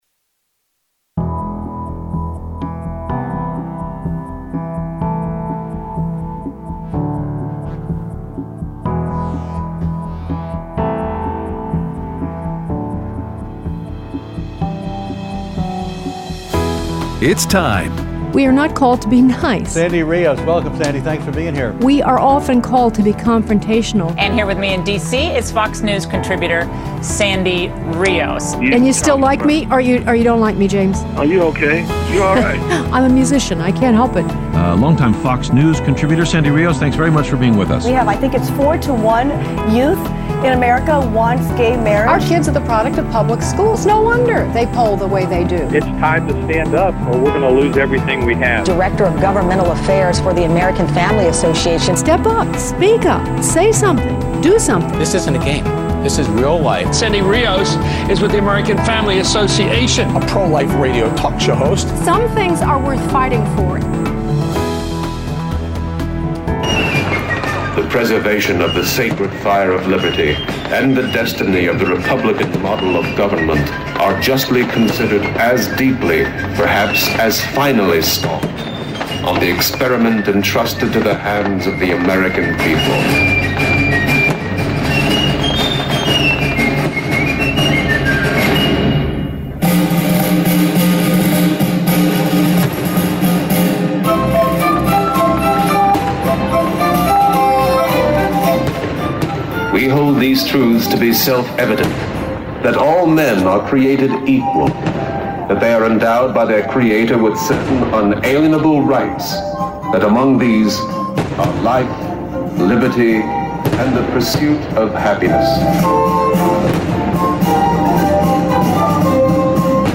Interview with Star Parker on Emancipation, Statues, Destroying History, and Her Book: Necessary Noise
Aired Monday 7/13/20 on AFR 7:05AM - 8:00AM CST